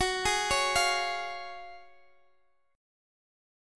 F#M7sus2 Chord